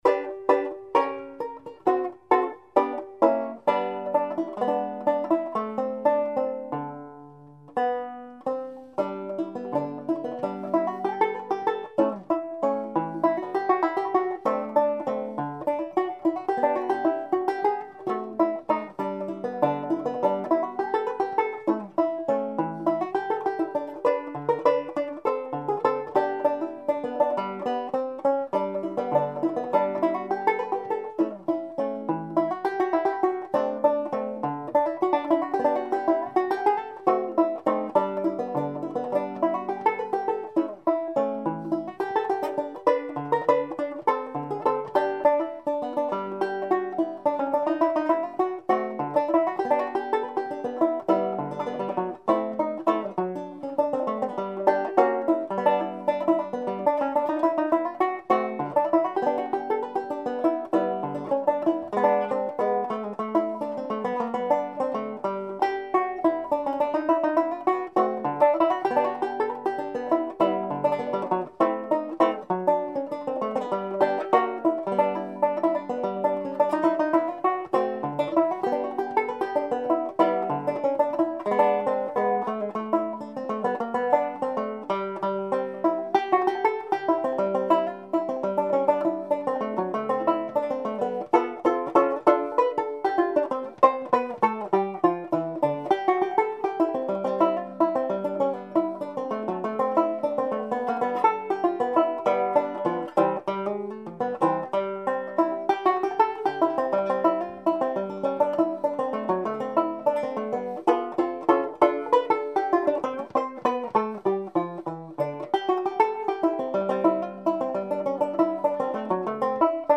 Tenor Banjo
In style, the pieces cover ragtime, classical and jazz.